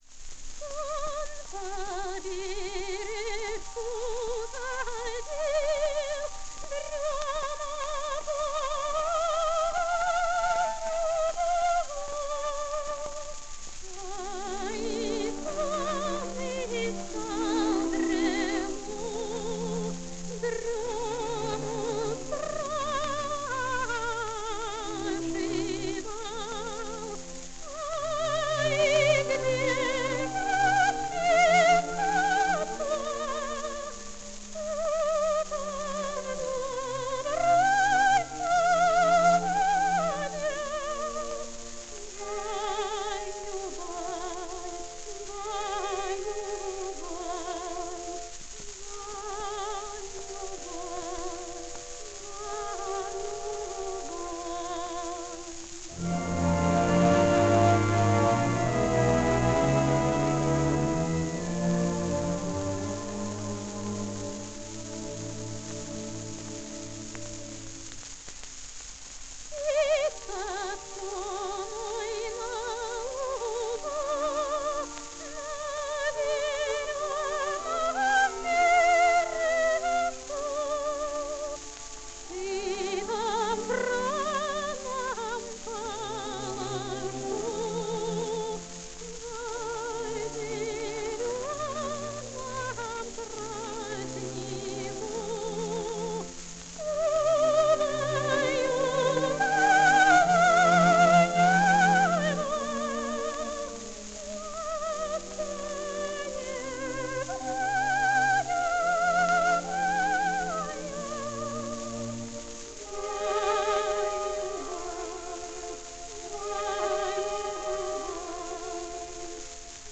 Жанр: Opera
В исполнении отечественных певиц.